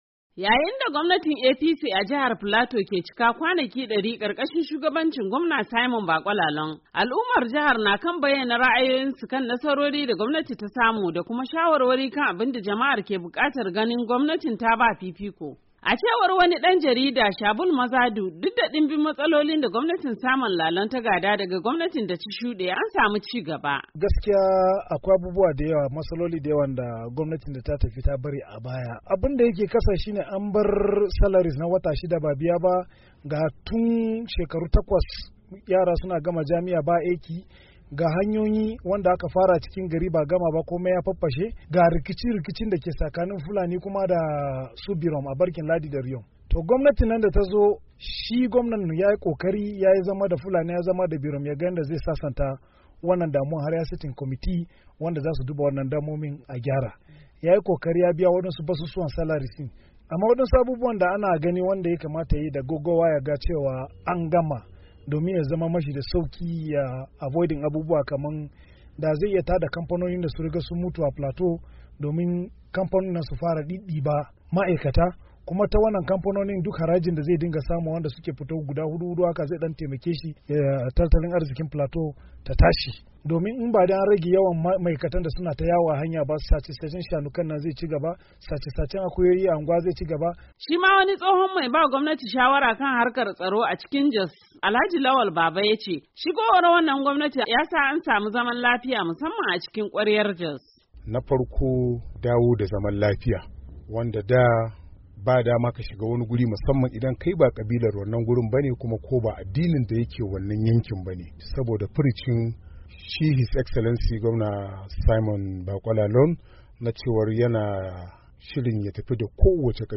Ga cikakken rahoton